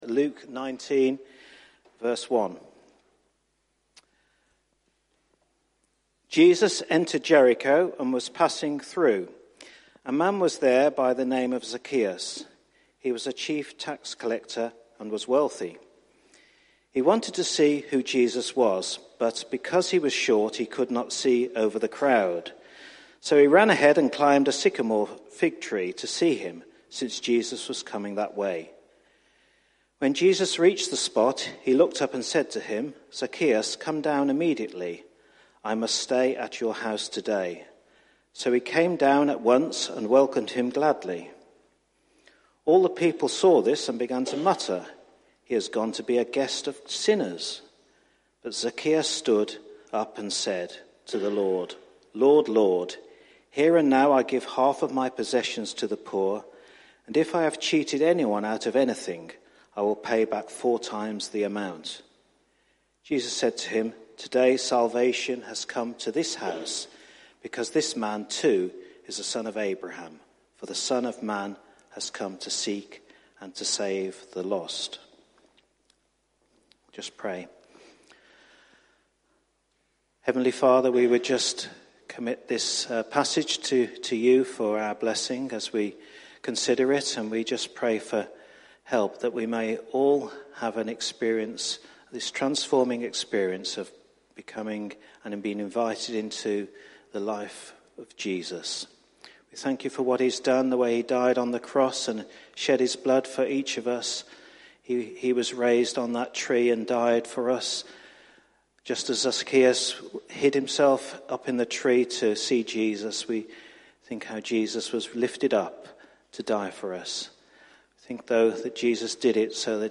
Service Sunday Morning